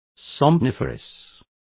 Complete with pronunciation of the translation of somniferous.